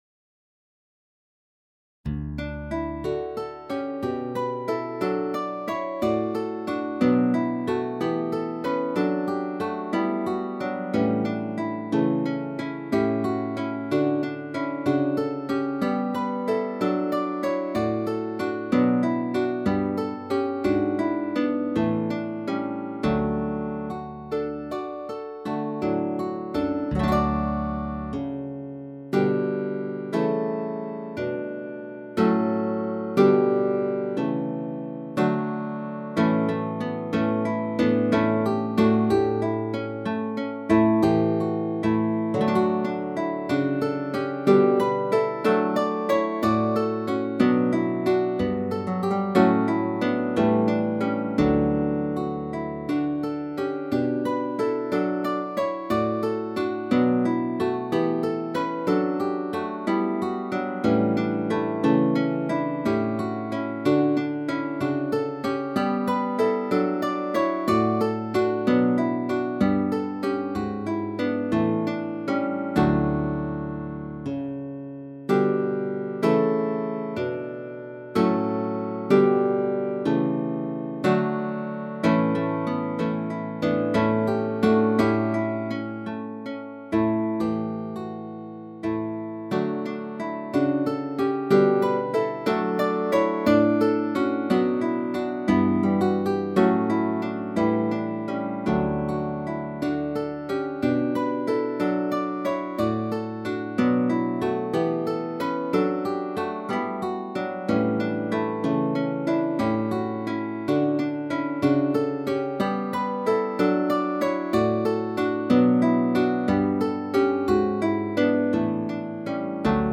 be more complicated (hence, also the low tempo).
The instrument is a guitar.
Per guitar voice 2 tracks.
Guitar is mixed acoustically with Orchestral Harp, so that a full sound is generated.
The dialectic of minor and major key is emphasised.